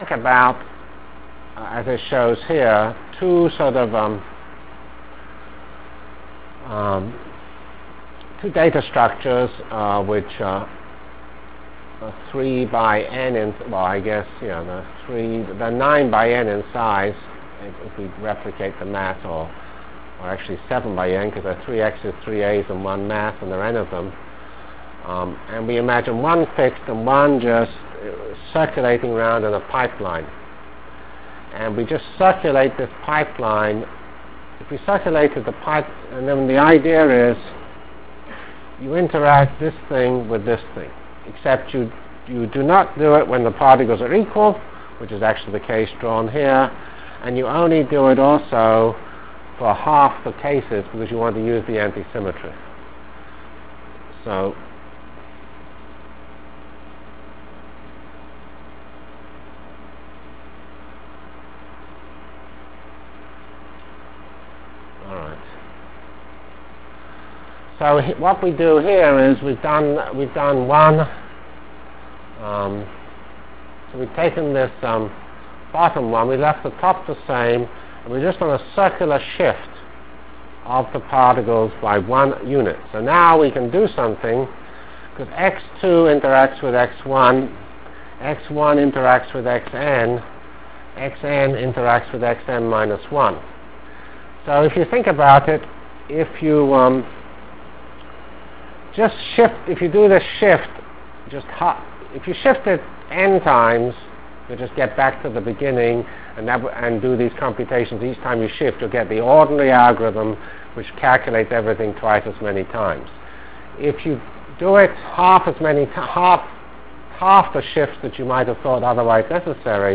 Delivered Lectures of CPS615 Basic Simulation Track for Computational Science